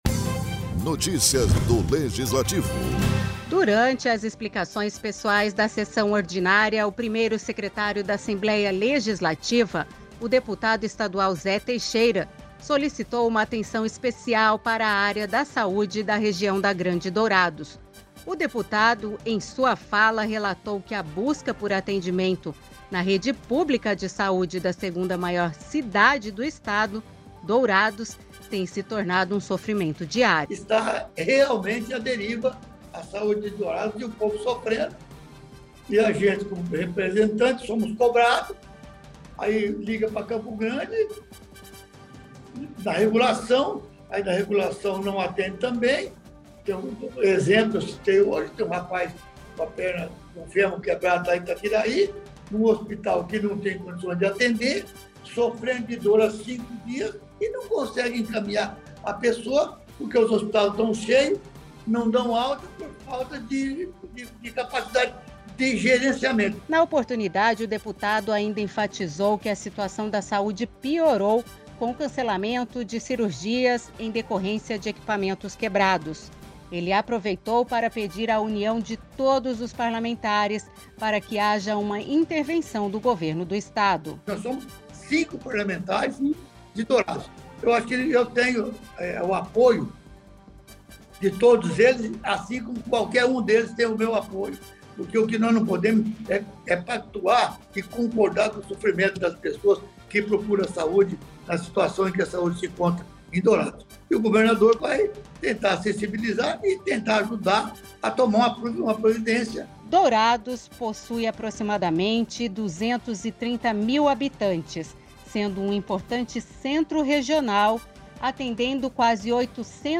Durante as explicações pessoais da sessão ordinária, o 1º secretário da Assembleia Legislativa, o deputado estadual Zé Teixeira (DEM), solicitou uma atenção especial para a área da saúde da Região da Grande Dourados.